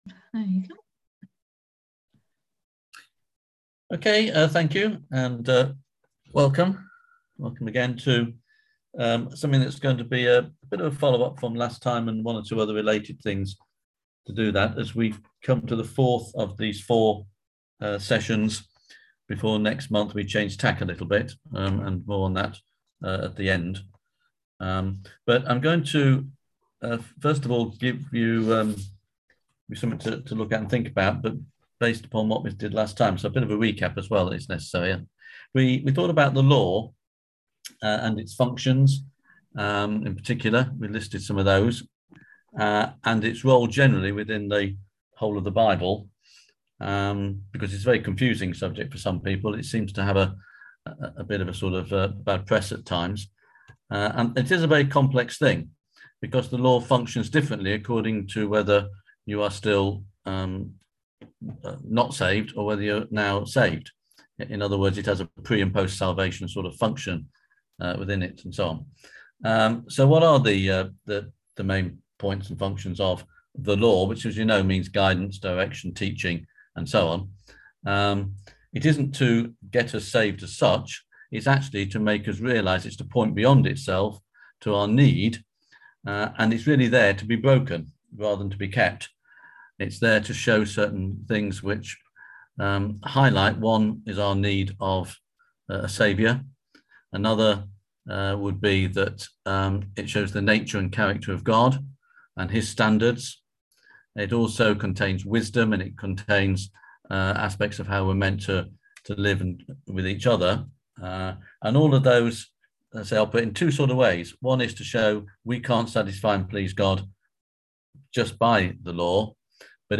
On January 27th at 7pm – 8:30pm on ZOOM